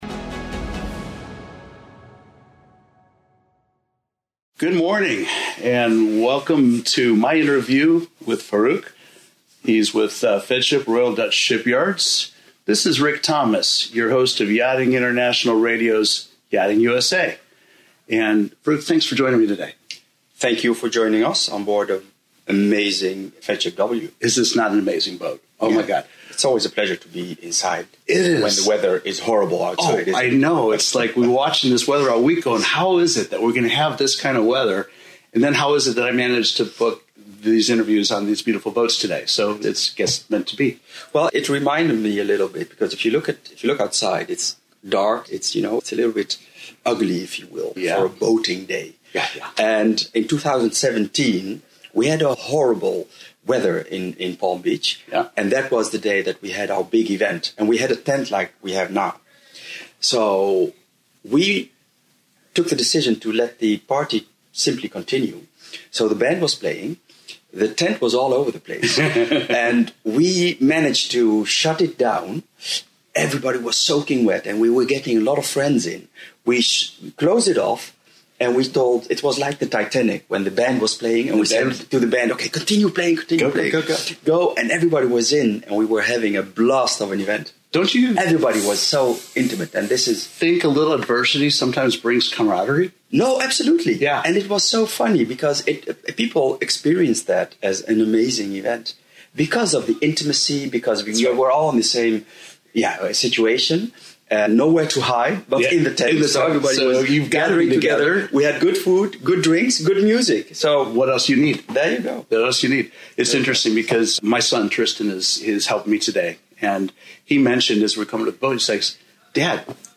Embark on a thought-provoking discussion on the importance of cross-generational mentorship and the enriching culture of the yachting industry. From crew well-being to yacht design trends, delve into the intricacies shaping the future of yachting.